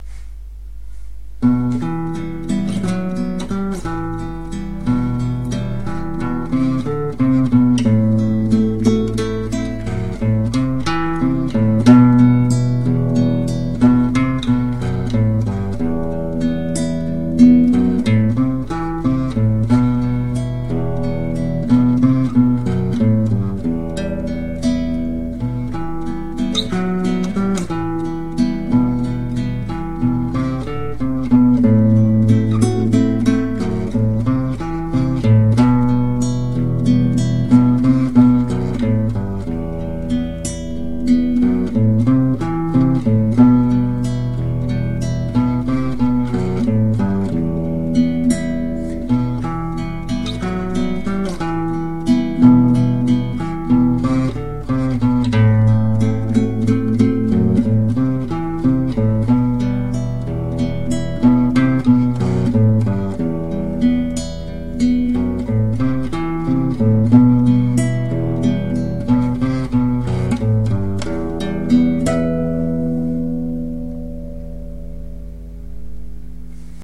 Вальс
Инструментальная пьеса